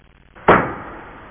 Switch Sound Effect
switch.mp3